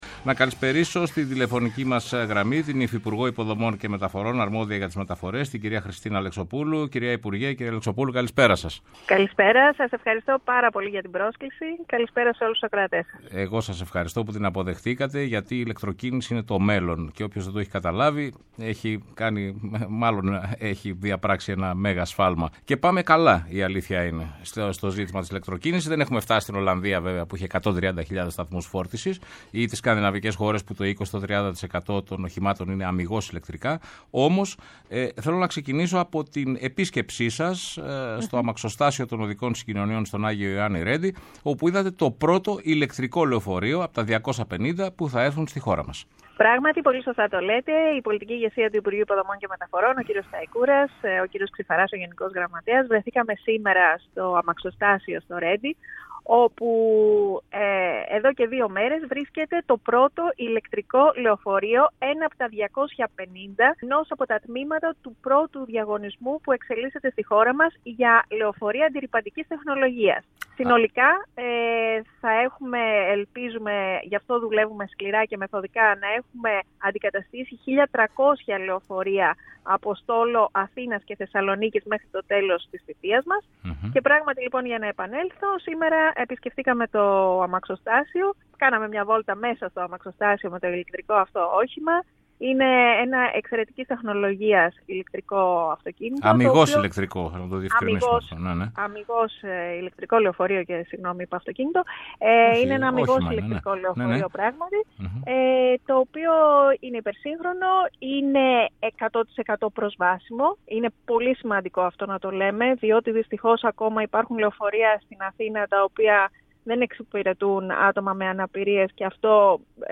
Η υφυπουργός Χριστίνα Αλεξοπούλου στο Πρώτο Πρόγραμμα | 22.02.2024